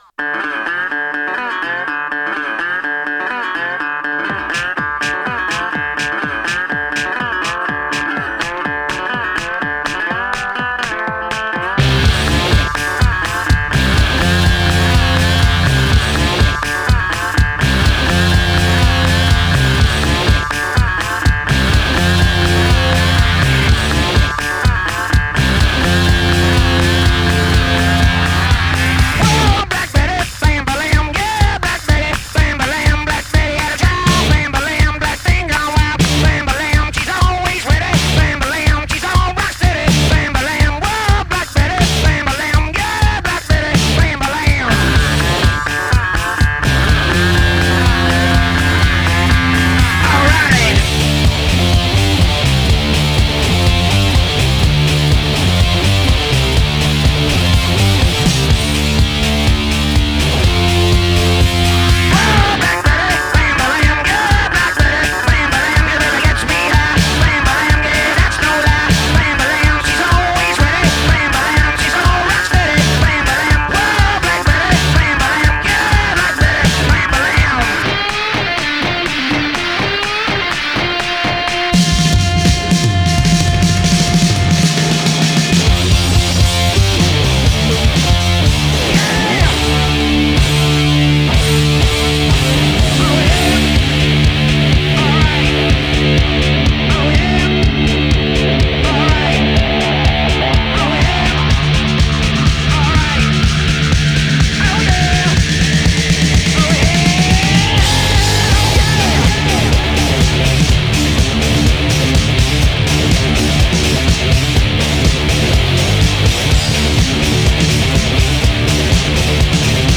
Жанр: Rock
Качество: 44Khz, 214Kbps, Stereo